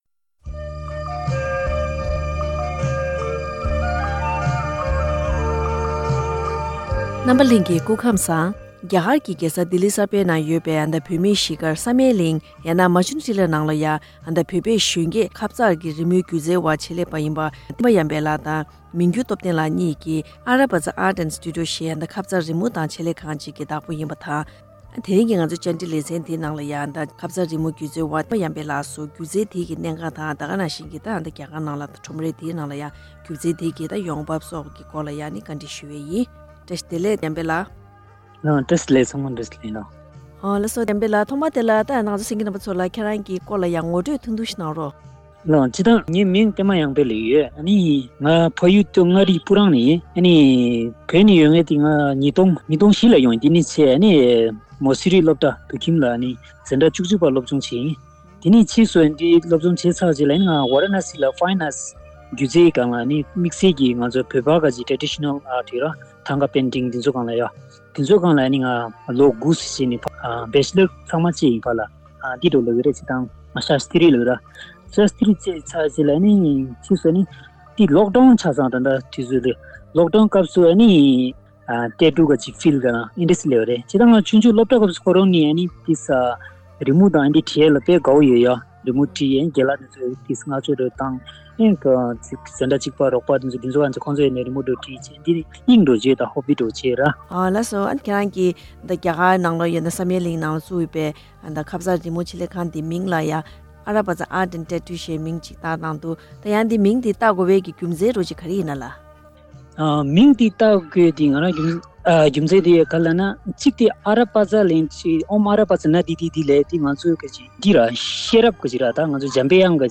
བཀའ་དྲི་བཞུ་བ